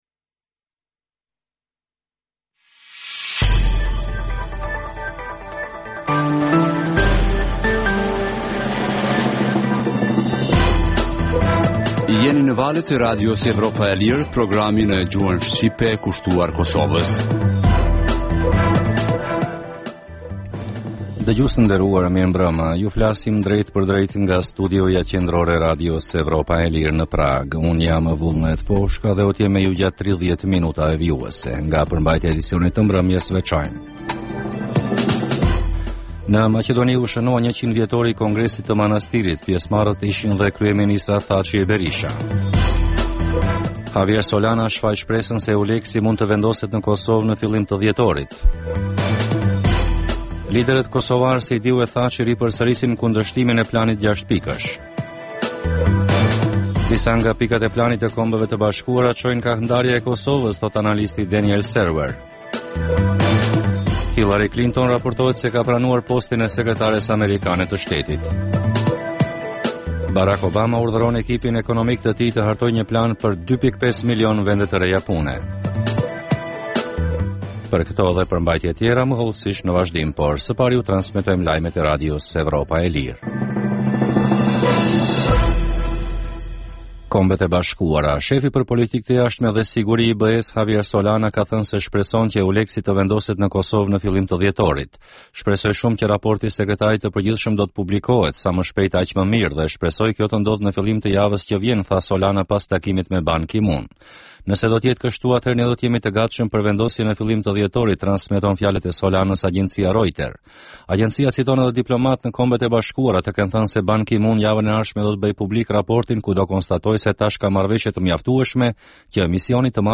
Emisioni i orës 21:00 është rrumbullaksim i zhvillimeve ditore në Kosovë, rajon dhe botë. Rëndom fillon me buletinin e lajmeve dhe vazhdin me kronikat për zhvillimet kryesore politike të ditës. Në këtë edicion sjellim intervista me analistë vendor dhe ndërkombëtar për zhvillimet në Kosovë, por edhe kronika dhe tema aktuale dhe pasqyren e shtypit ndërkombëtar.